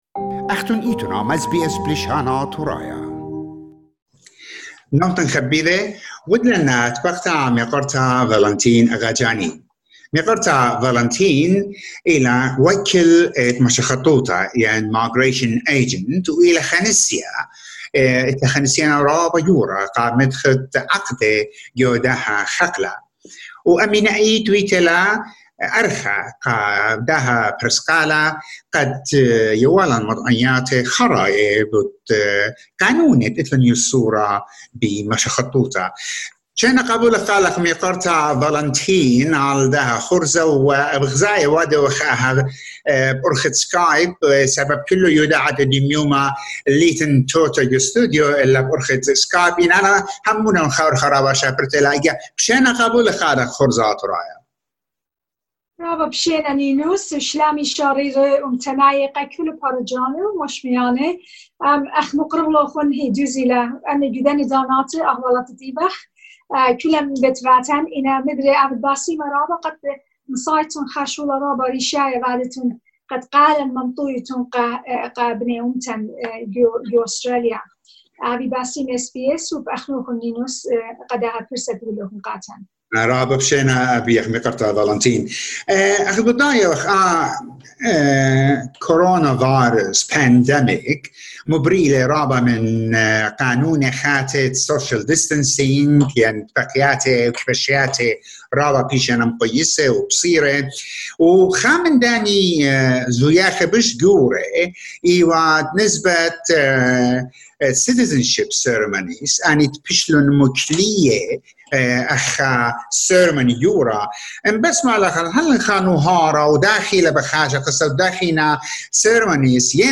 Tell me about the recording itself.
In a video interview done through zoom